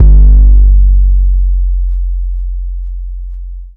Hard luger Trunk 808.wav